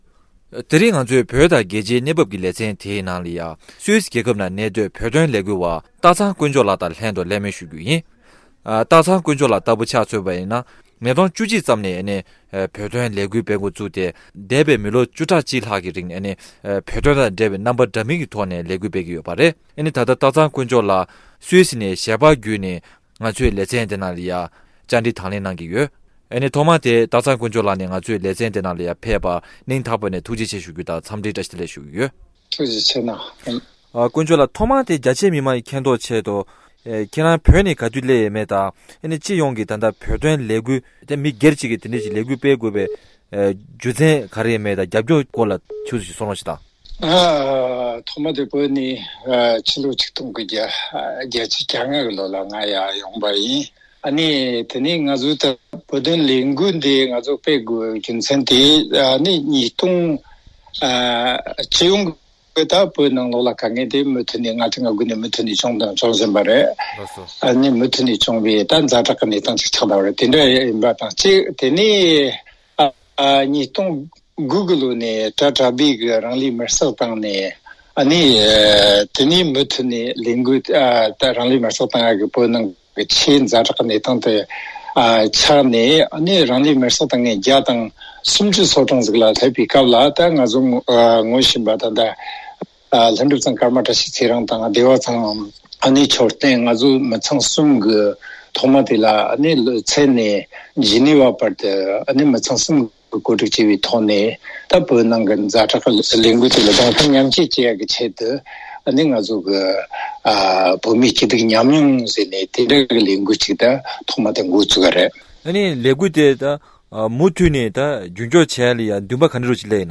གླེང་མོལ་ཞུས་པ།